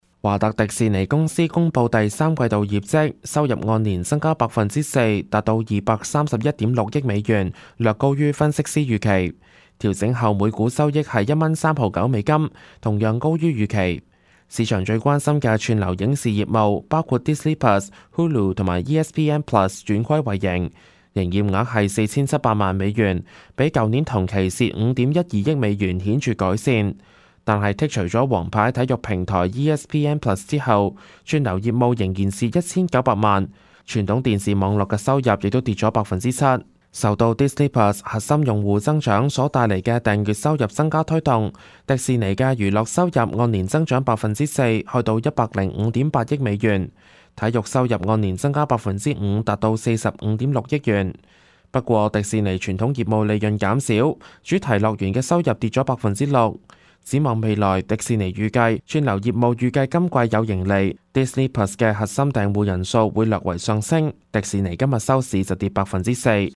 news_clip_20071.mp3